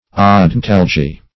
odontalgy - definition of odontalgy - synonyms, pronunciation, spelling from Free Dictionary Search Result for " odontalgy" : The Collaborative International Dictionary of English v.0.48: Odontalgy \O`don*tal"gy\, n. (Med.)
odontalgy.mp3